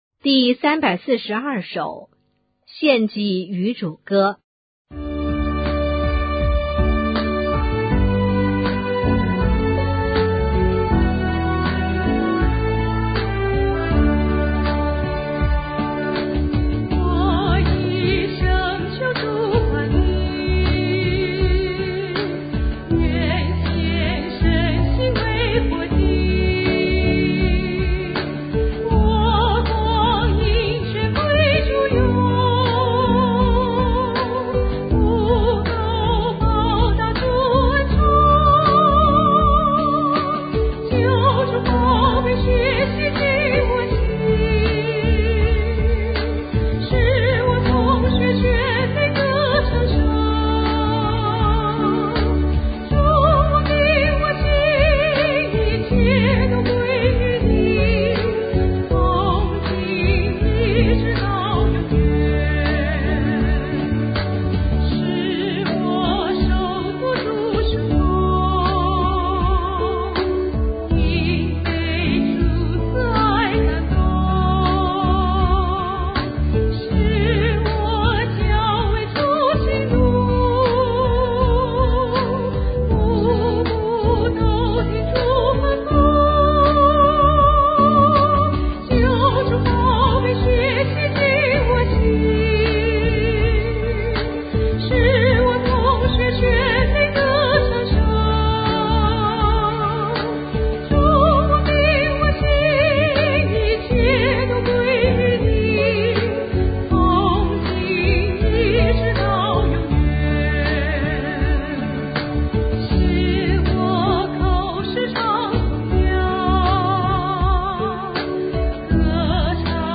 赞美诗《献己于主歌》